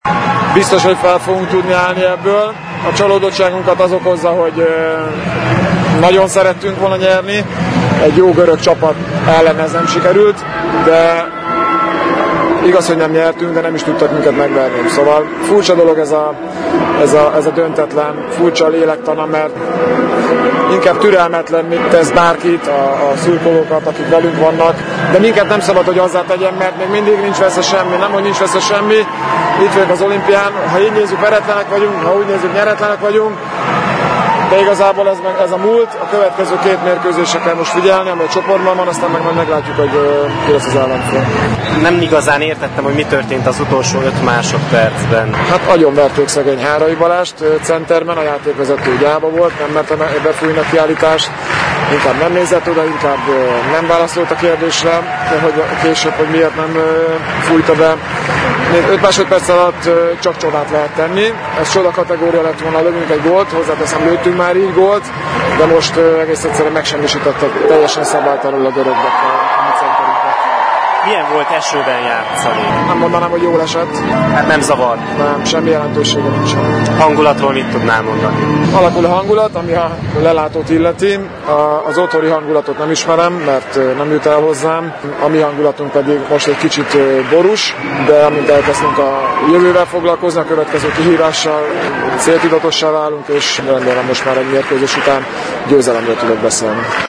Varga Dániel láthatóan csalódott volt az újabb döntetlen miatt, de fejben már megpróbált készülni a Japán elleni mérkőzésre.